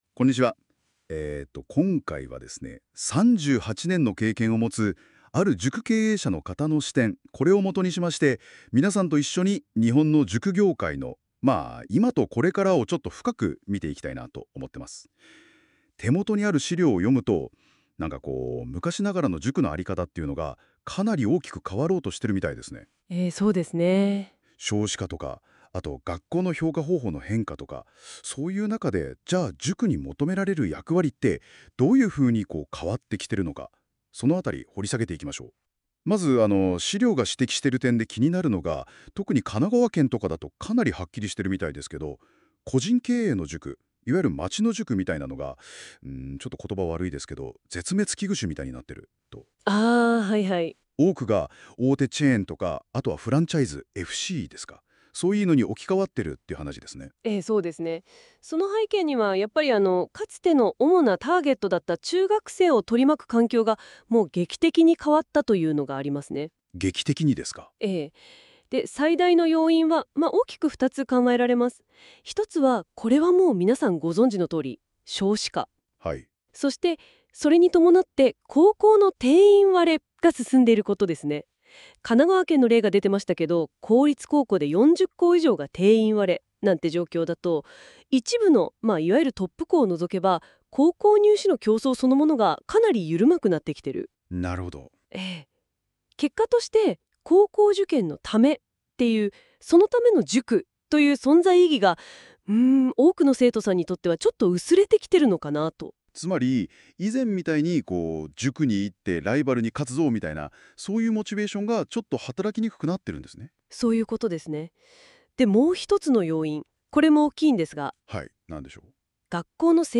個人塾の生き残り戦略についいての10回の発信を10数分にAIにまとめさせました。同時にAIの音声でラジオ風の対